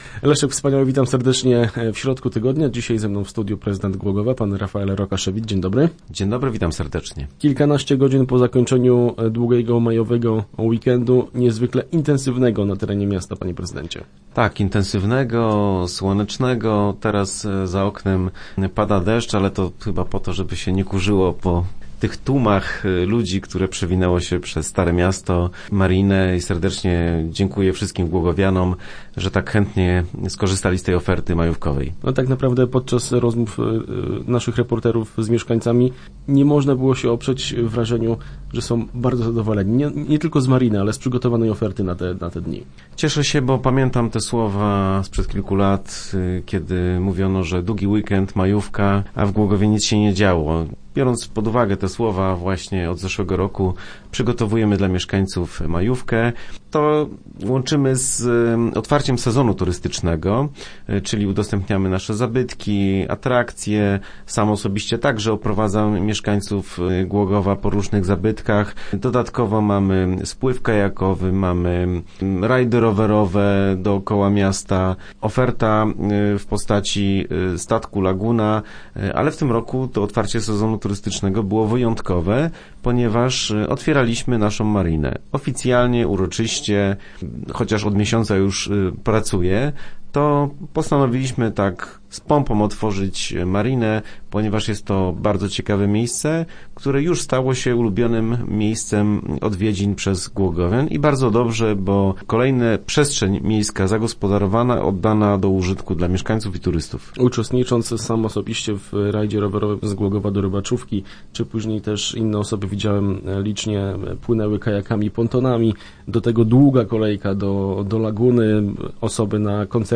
Mówił o tym studiu prezydent miasta Rafael Rokaszewicz.